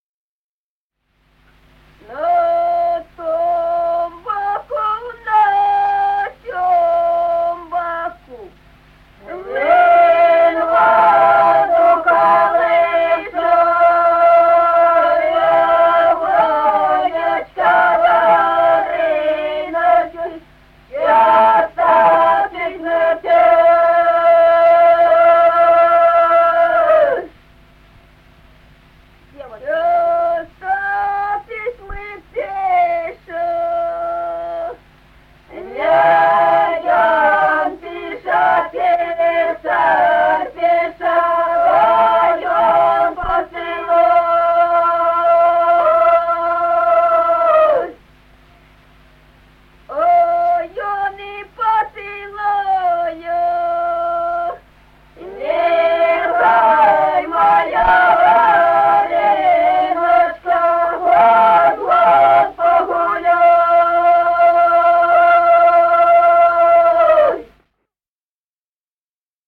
Музыкальный фольклор села Мишковка «На том боку, на сём боку», петровская.